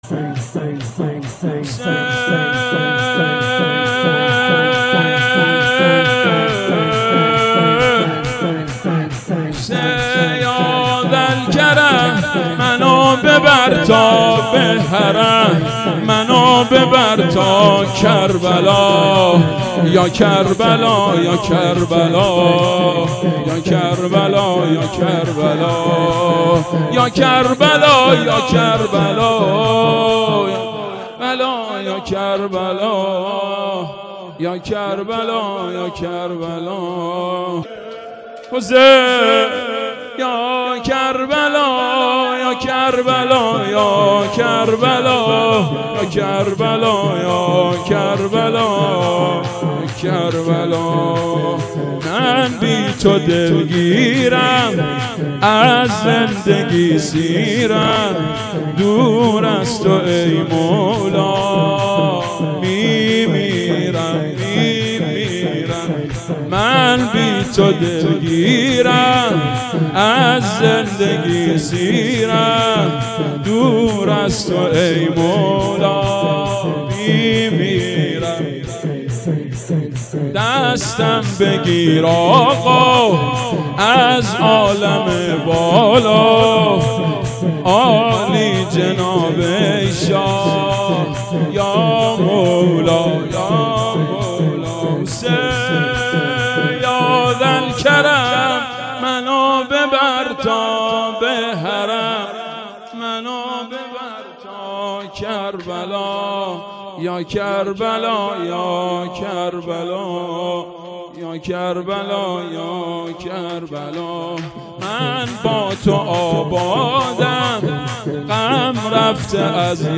شور آخر(یا کربلا)شهادت امیرالمومنین ع هیئت محبین العباس و فاطمیون و هیئت الرضا ملاثانی.mp3